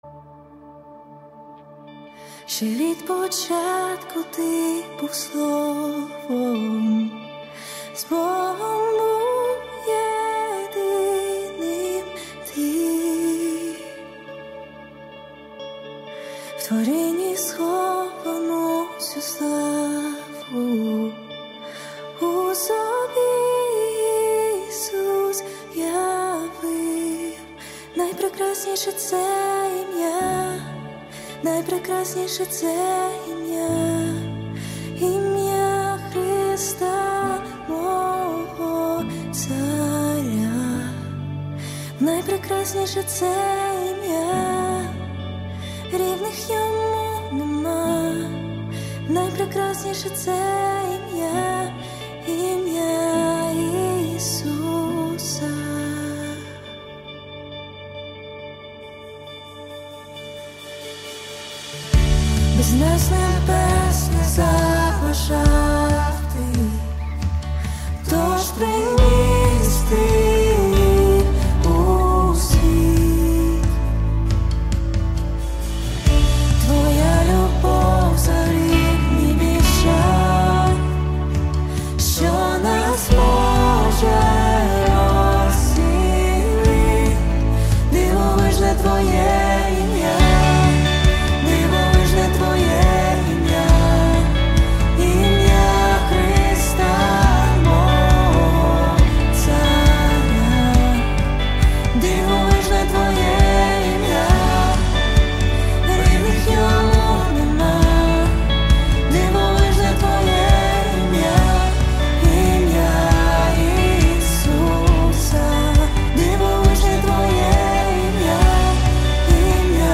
240 просмотров 39 прослушиваний 22 скачивания BPM: 136